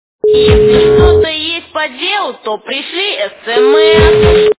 » Звуки » Люди фразы » Если что-то есть по делу, - То пришли SMS
При прослушивании Если что-то есть по делу, - То пришли SMS качество понижено и присутствуют гудки.